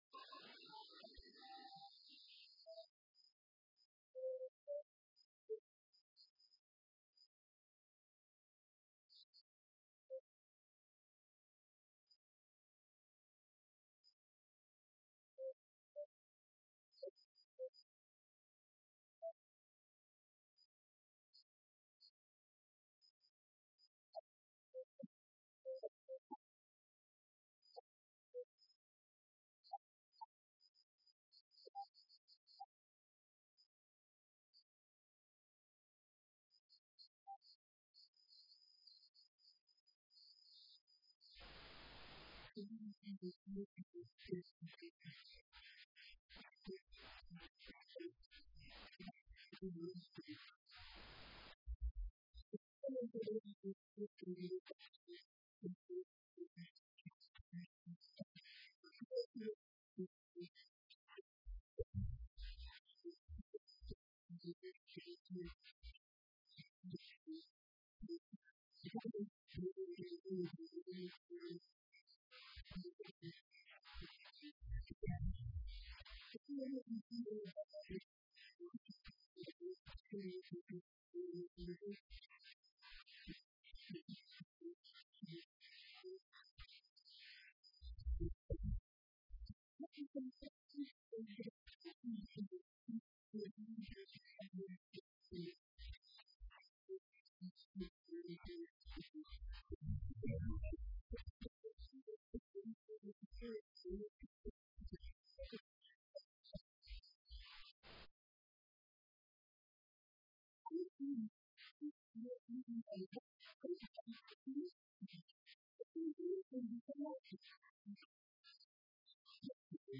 Dhageyso; Warka Subax ee Radio Muqdisho